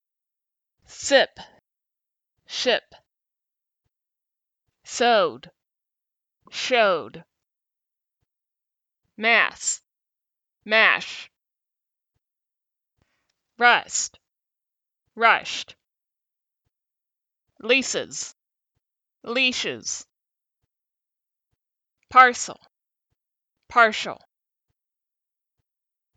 These are both voiceless, fricative consonants.
When you pronounce /s/, your tongue is slightly farther forward than when you pronounce /ʃ/.
You can hear the difference between /s/ and /ʃ/ in these words.
compare_s-sh_words.mp3